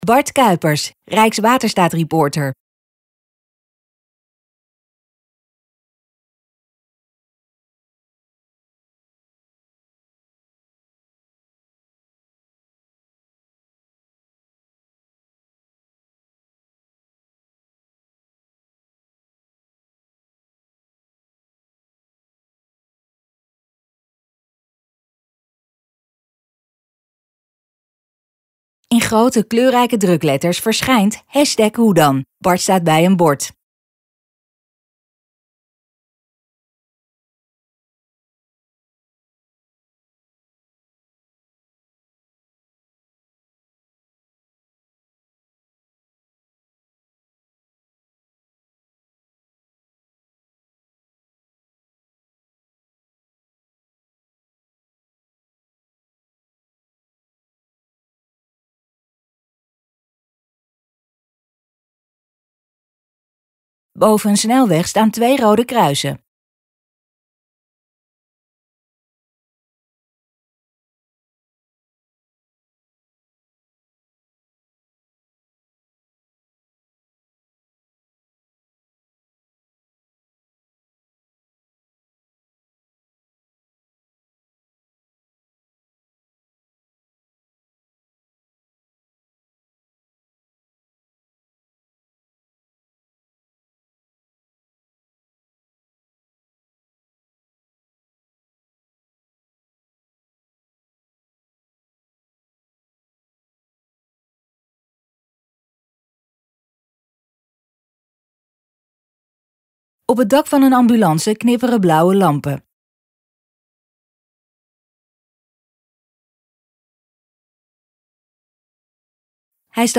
Hij spreekt met ambulancemedewerkers over medische spoedtransporten én kijkt mee in de verkeerscentrale. Na het kijken van deze video weet jij precies waarom er een rood kruis op de snelweg staat en waarom het zo belangrijk is.